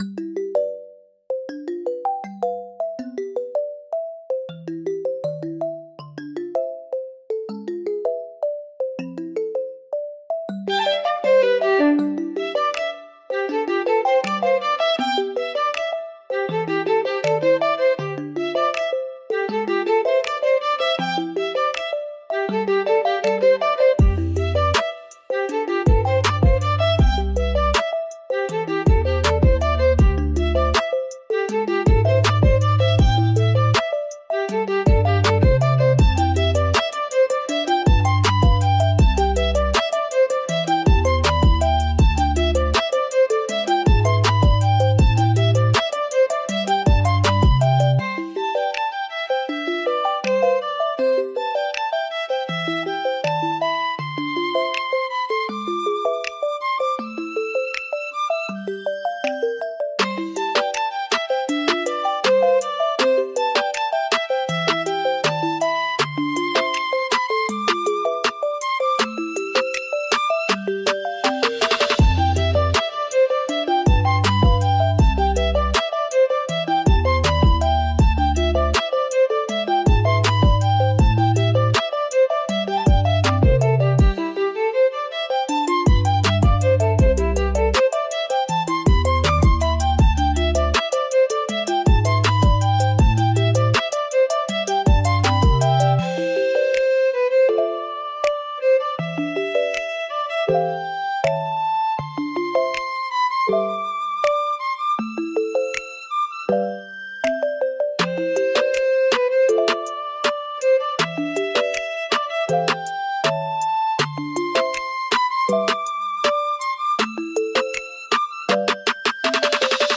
C Major – 80 BPM
Lofi
Trap